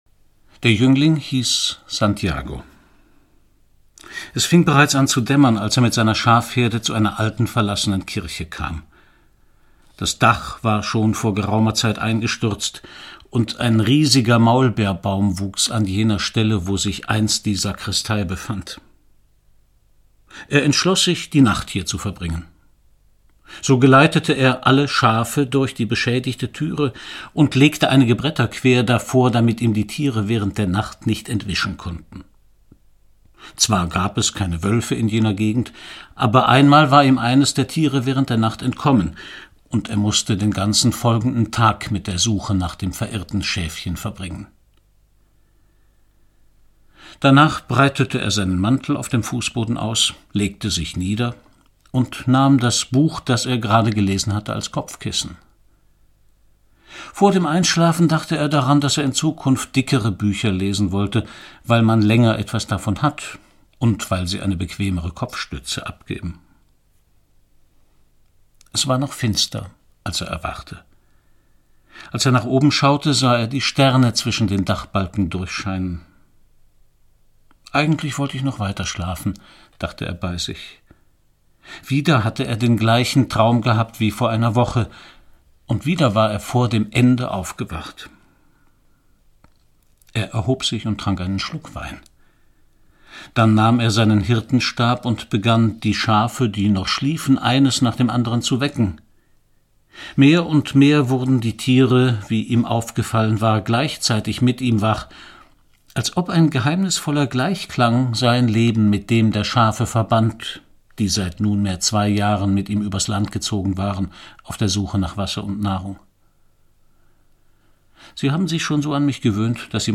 Der Alchimist - Paulo Coelho - Hörbuch